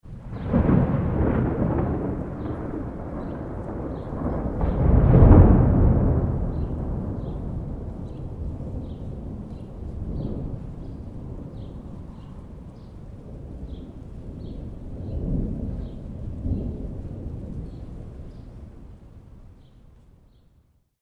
thunder_24.ogg